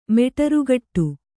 ♪ meṭarugaṭṭu